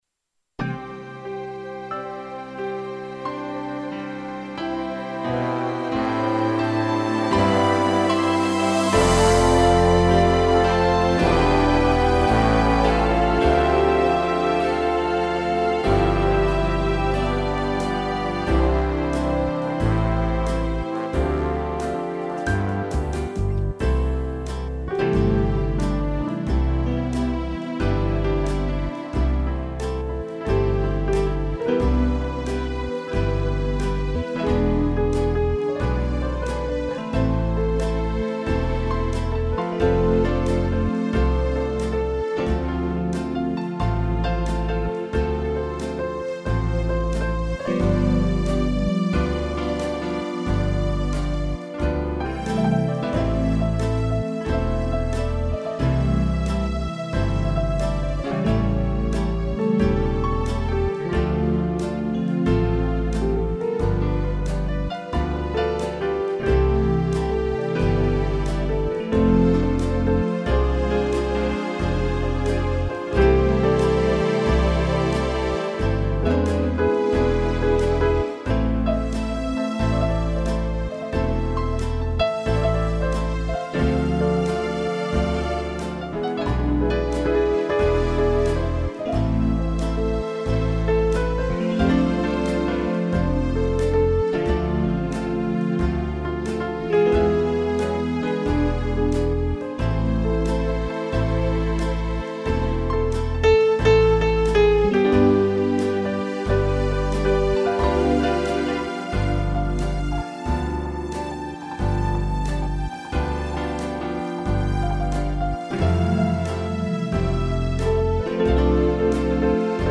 arranjo e interpretação teclado